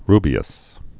(rbē-əs)